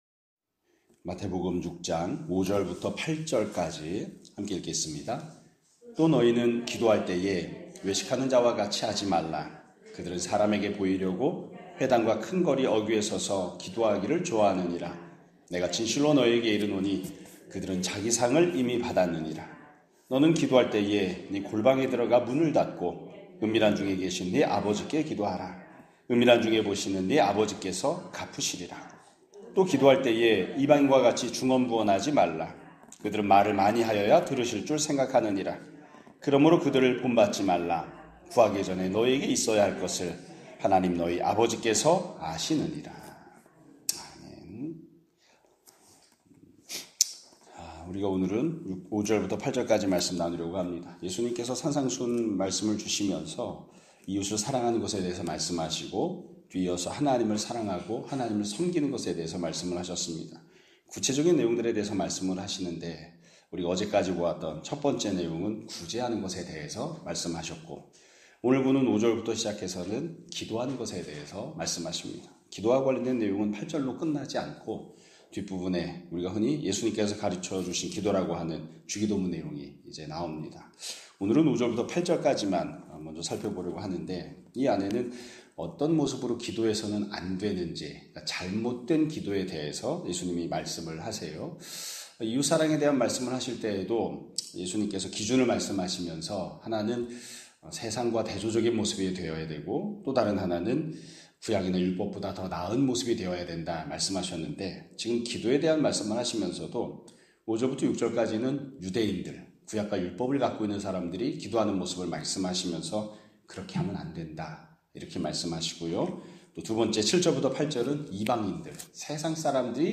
2025년 6월 12일(목요일) <아침예배> 설교입니다.